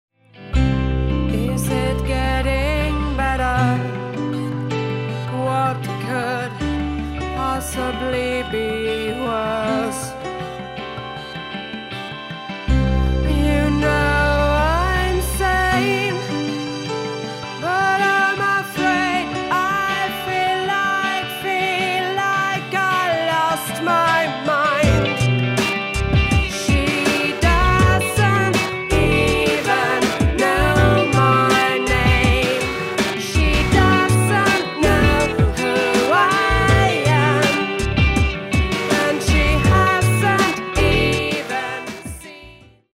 I say their music is Alt Pop Rock
It was a fun and creative session.